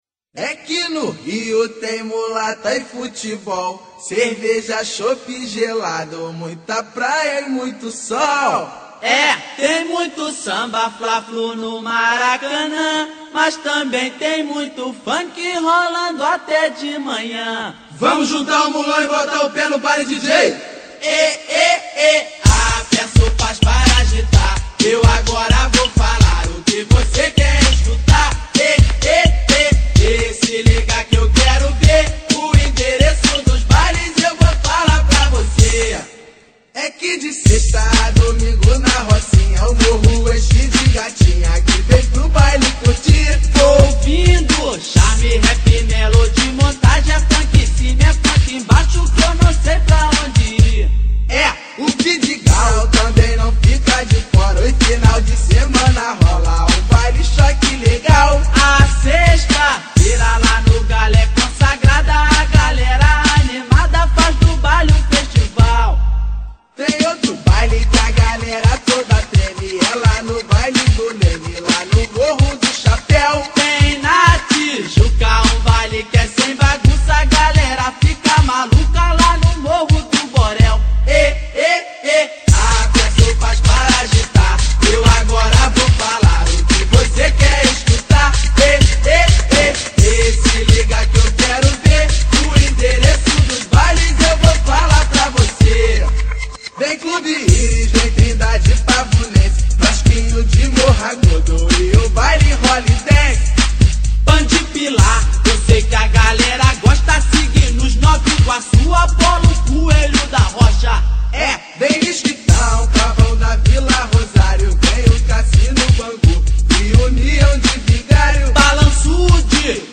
Funk Para Ouvir: Clik na Musica.